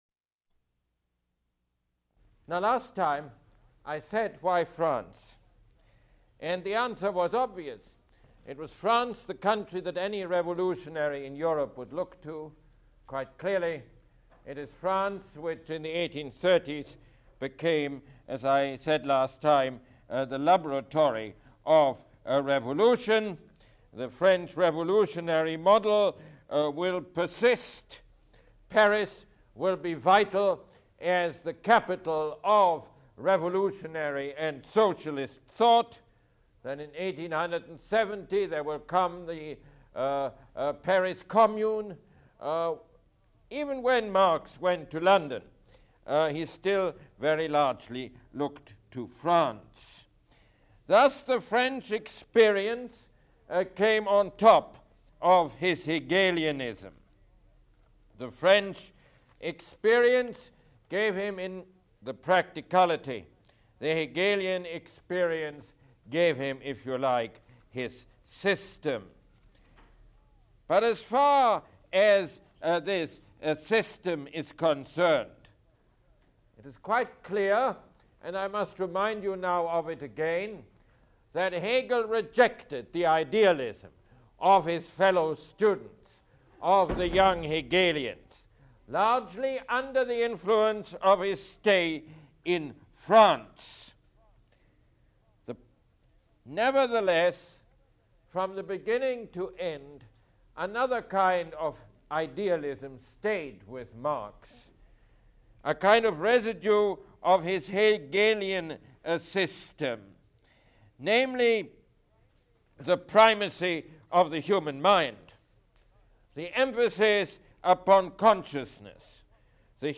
Mosse Lecture #30